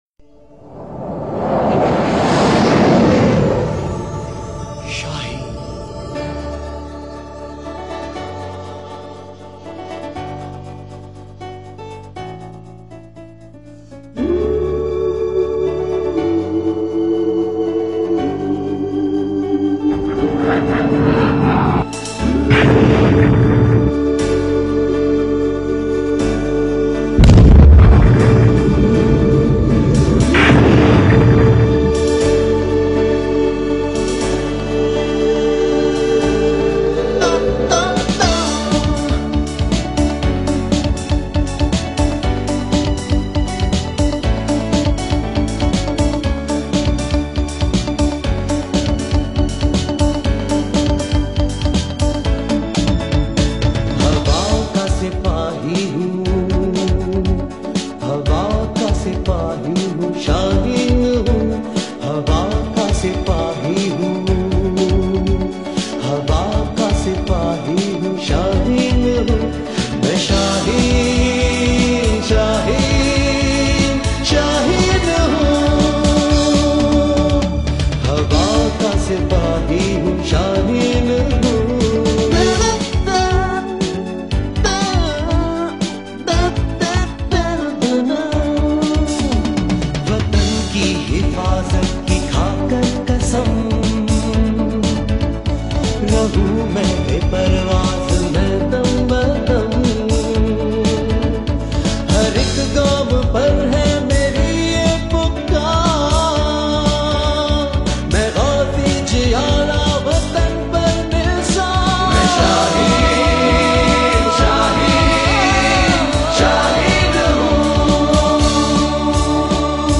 Click Play to Listen This Naghma: